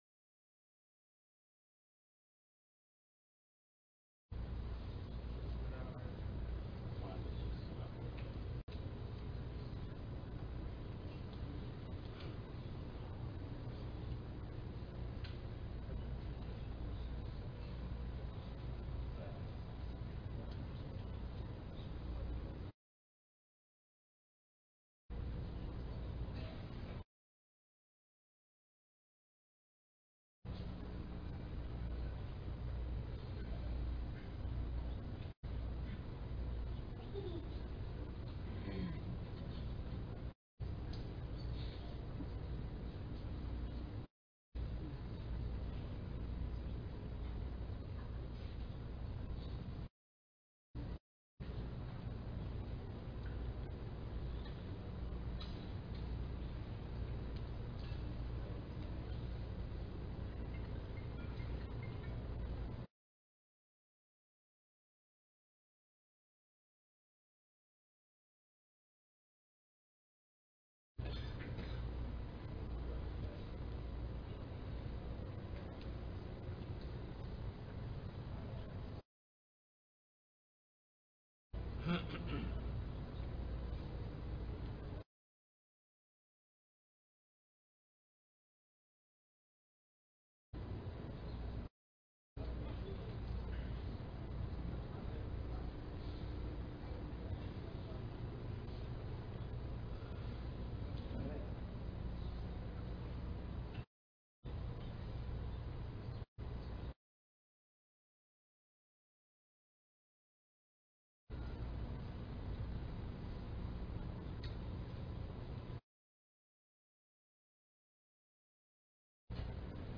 إعداد المقابر للمغتربين (خطب الجمعة